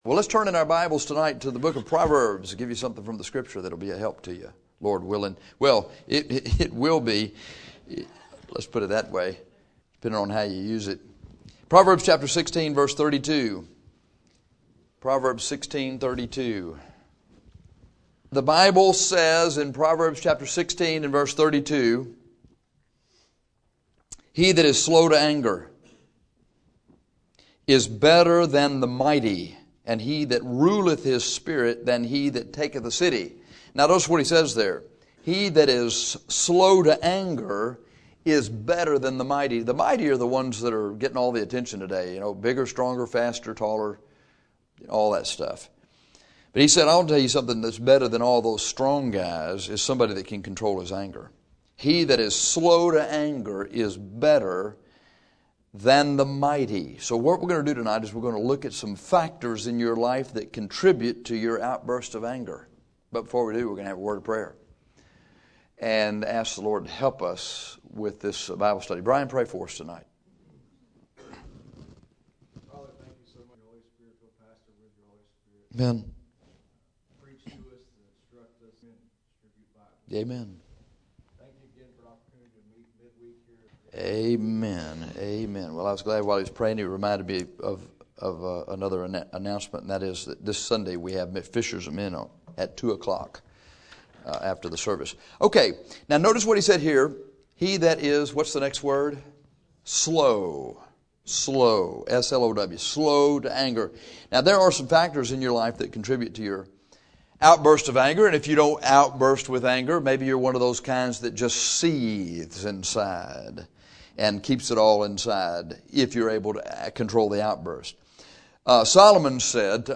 This lesson gives you four practical things to do.